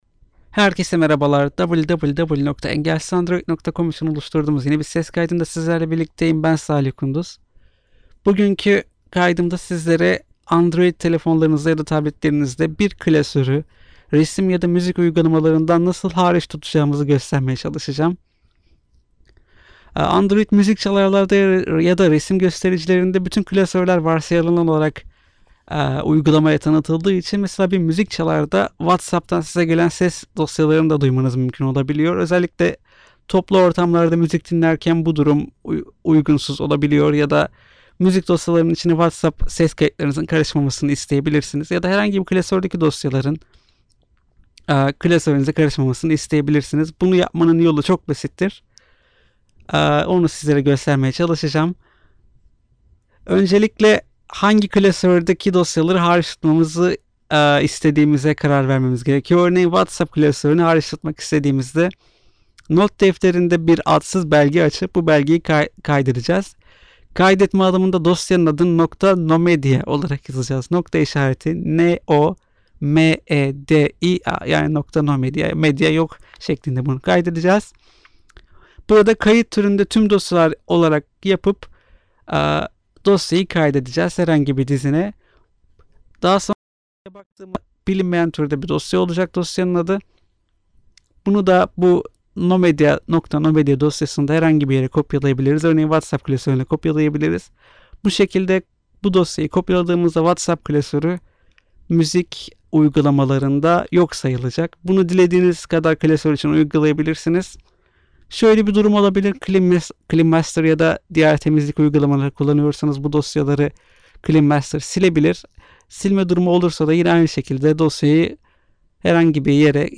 sesli anlatım - Medya uygulamalarında bir klasörü hariç tutmak.mp3